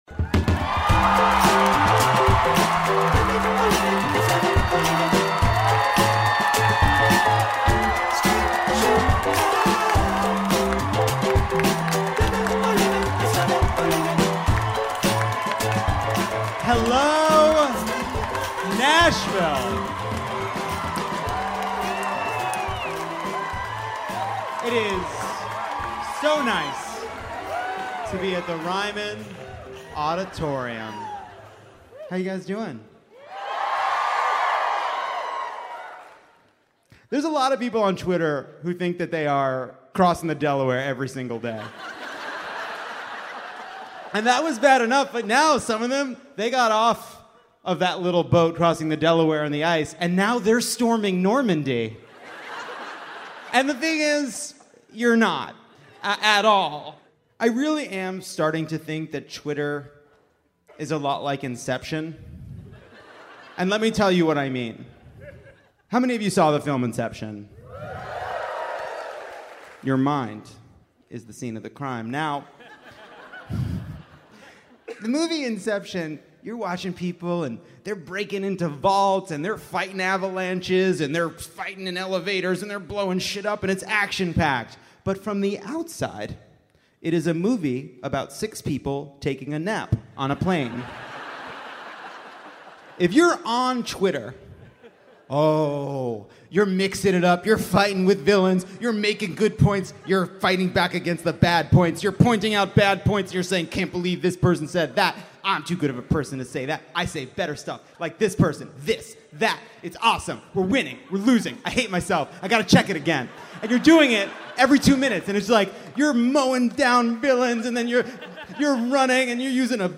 Recorded live at the historic Ryman Auditorium in Nashville